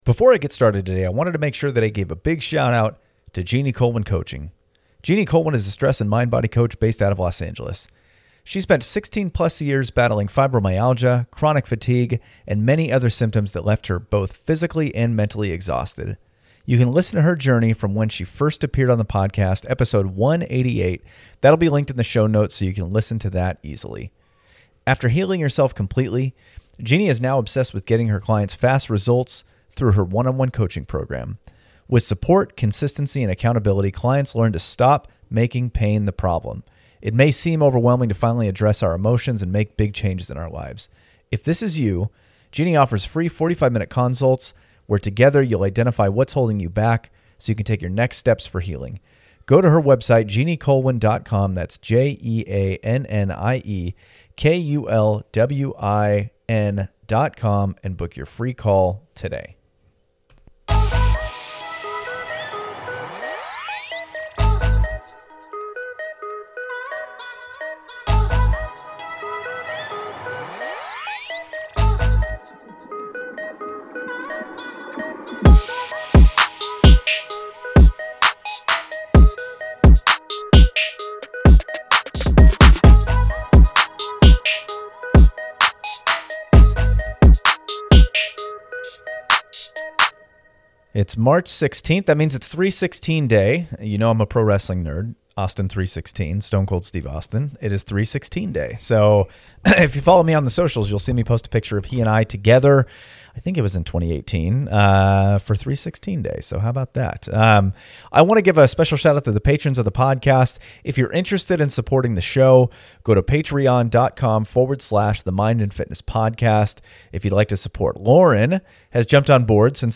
This is always such an honor to do - always a lot of fun and good questions towards the end of the episode.